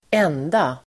Uttal: [²'en:da]